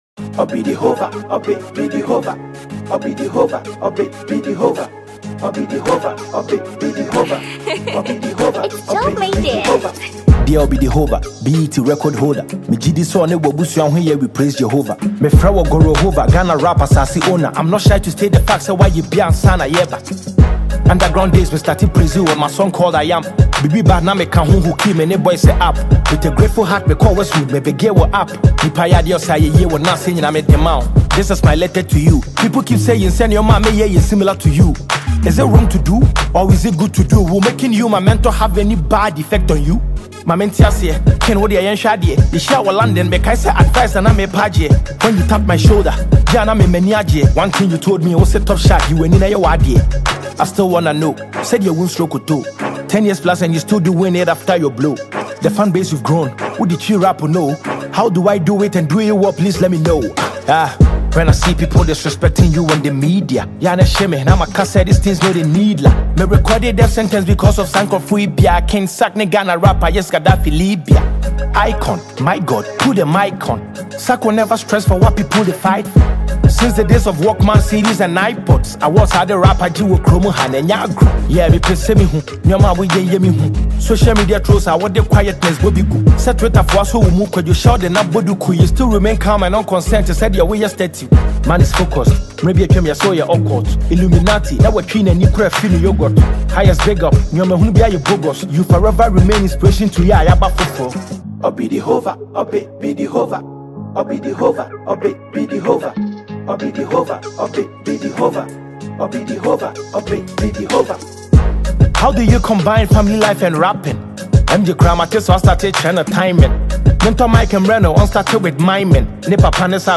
Ghanaian rapper
diss song